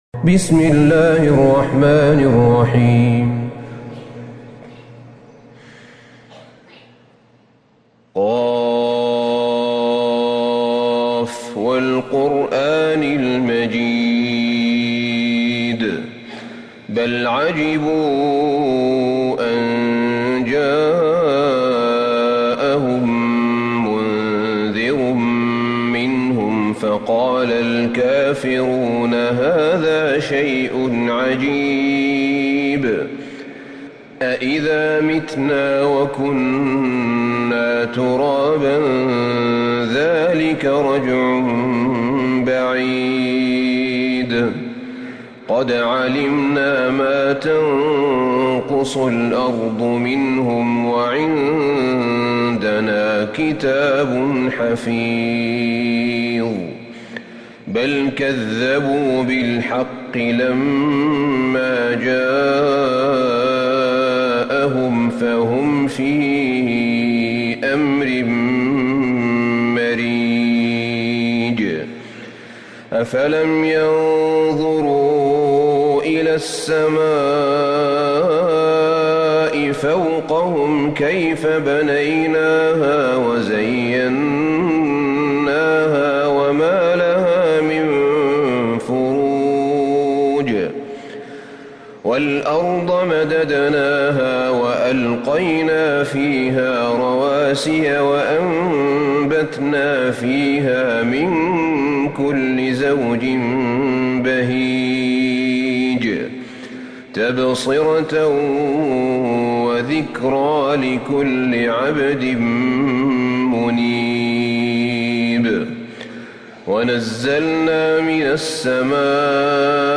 سورة ق Surat Qaf > مصحف الشيخ أحمد بن طالب بن حميد من الحرم النبوي > المصحف - تلاوات الحرمين